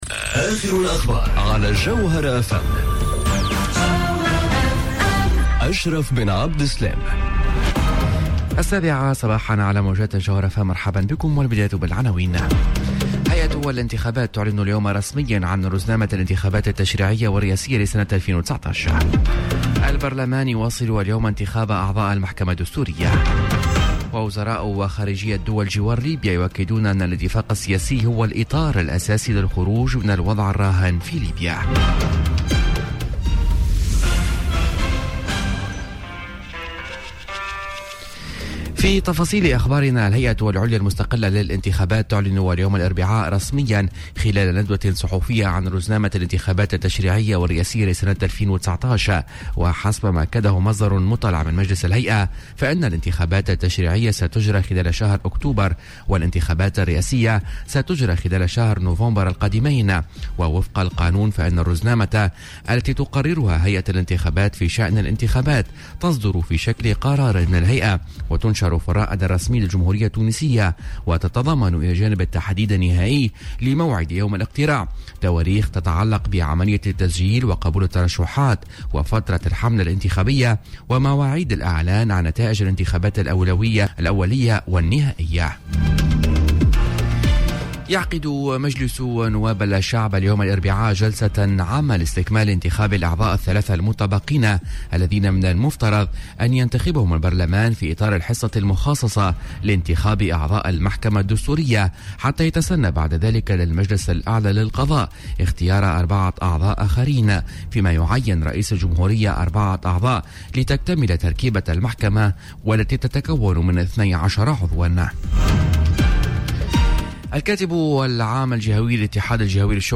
نشرة أخبار السابعة صباحا ليوم الإربعاء 06 مارس 2019